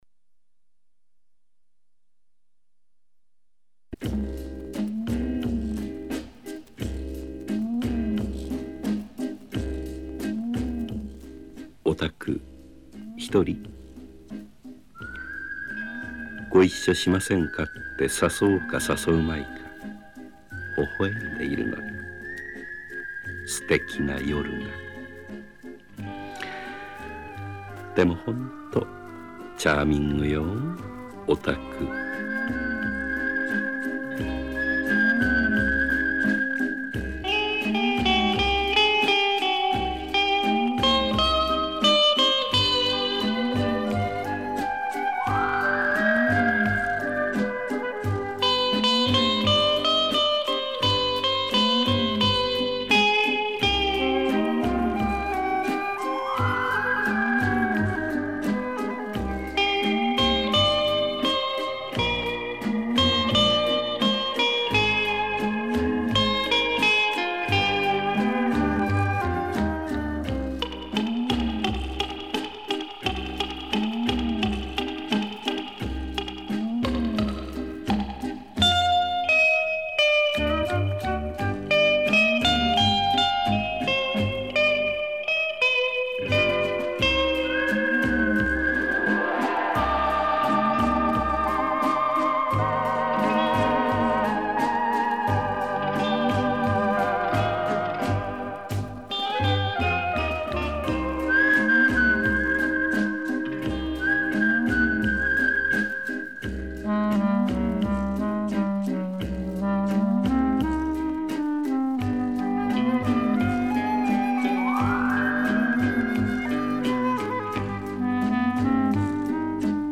Приятная музыка!